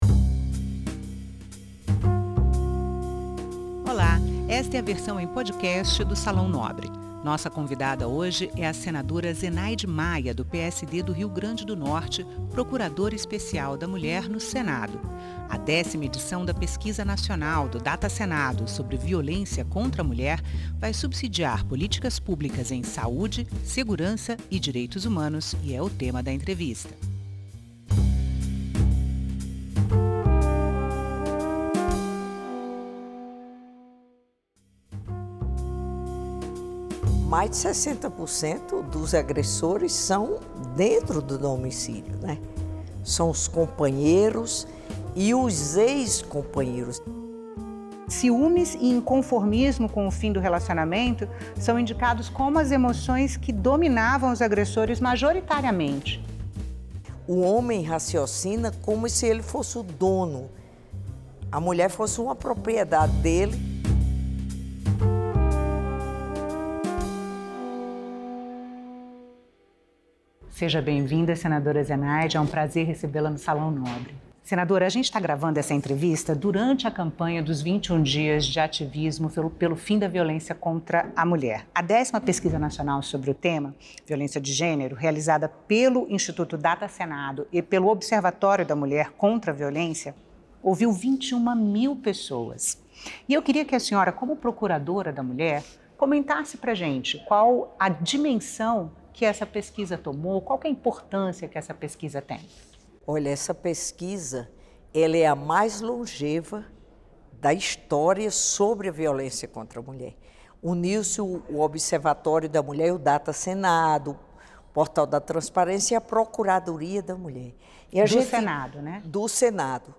Programa de entrevistas com temas de relevância nacional na opinião de líderes partidários, presidentes das comissões, autores e relatores de projetos.